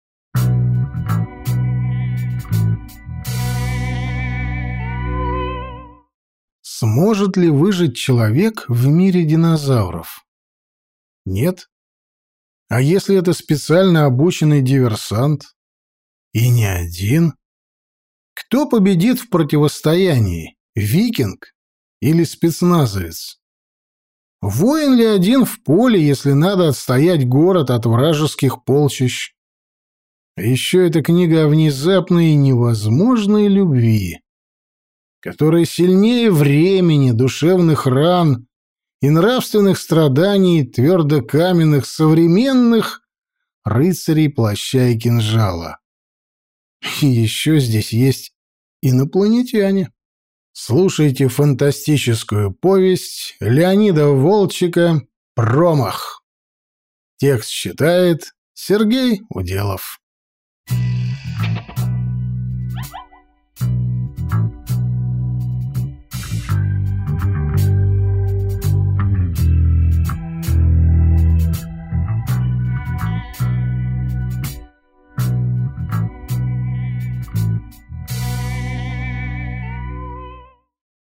Аудиокнига Промах | Библиотека аудиокниг
Прослушать и бесплатно скачать фрагмент аудиокниги